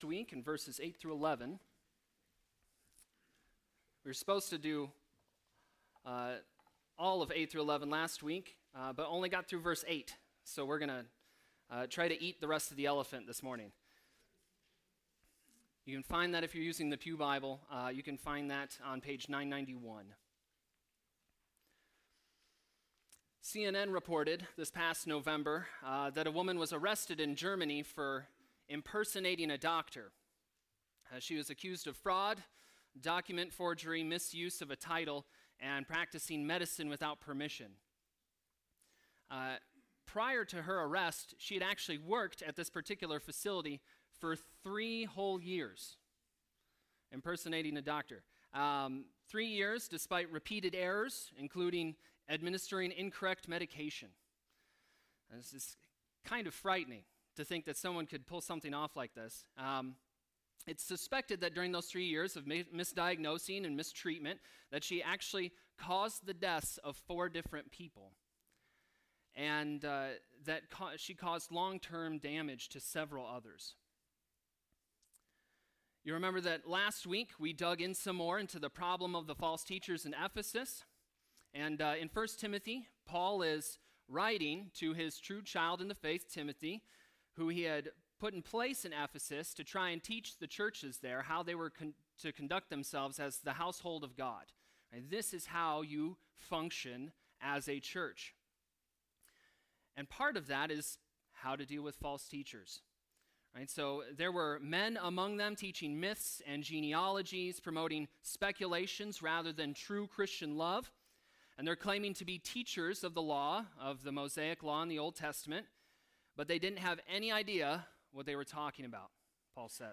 Sermon preached Sunday, February 2, 2020